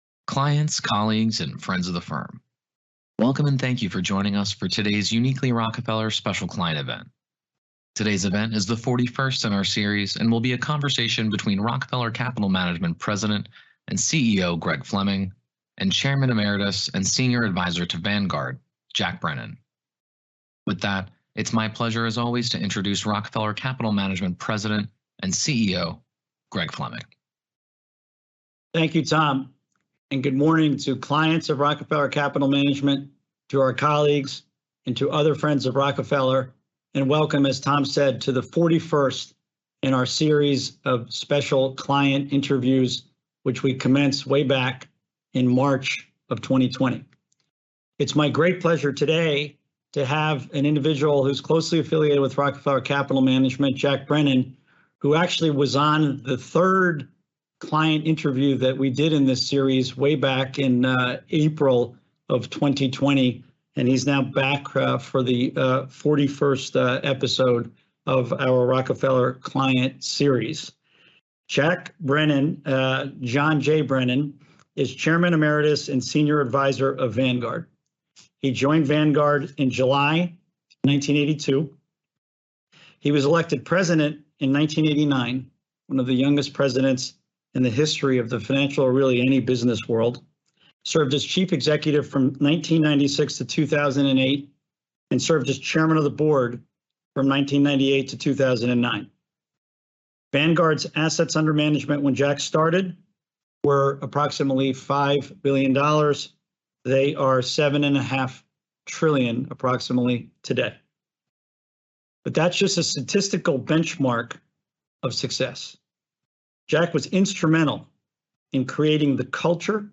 Listen in on conversations between Rockefeller Capital Management's Executive Leadership Committee including CEO Greg Fleming and key leaders of industry, valued clients and more in Rockefeller Capital Management's Client Insights Podcast series.